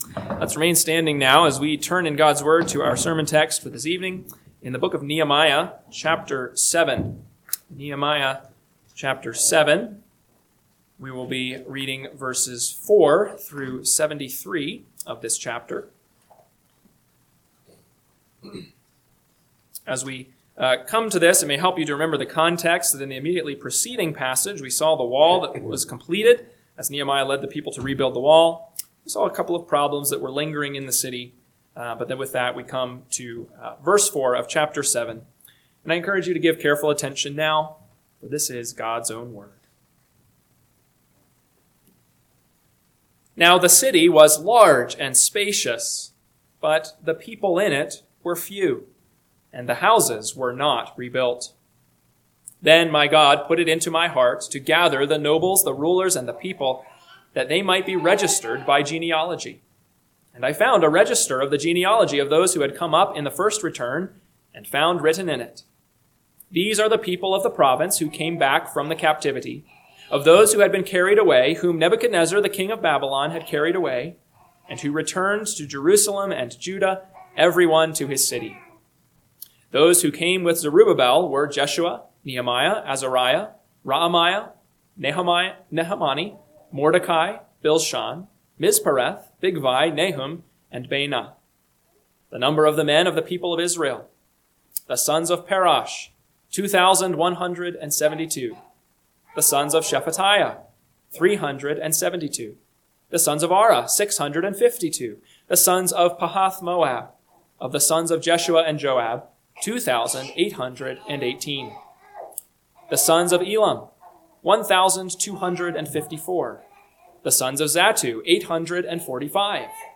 PM Sermon – 9/21/2025 – Nehemiah 7:4-73 – Northwoods Sermons